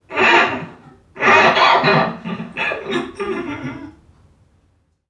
NPC_Creatures_Vocalisations_Robothead [56].wav